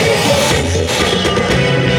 120BPMRAD5-R.wav